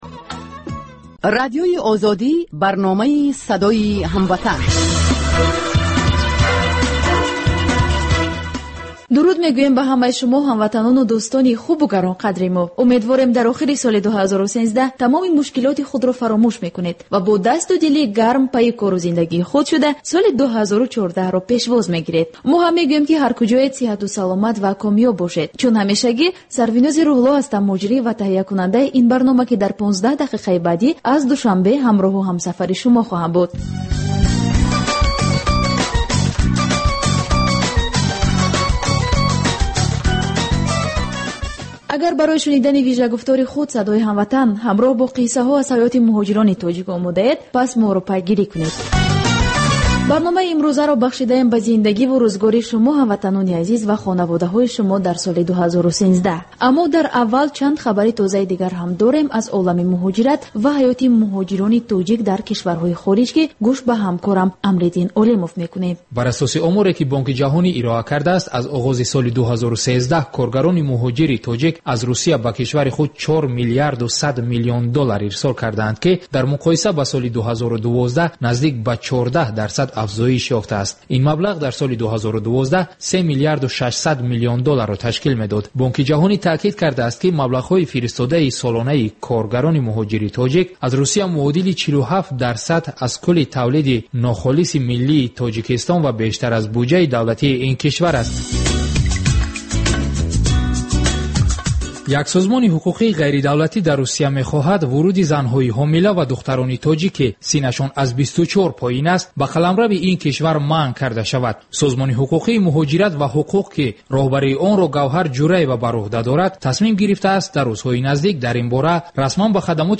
Тоҷикон дар кишварҳои дигар чӣ гуна зиндагӣ мекунанд, намунаҳои комёб ва нобарори муҳоҷирон дар мамолики дигар, мусоҳиба бо одамони наҷиб.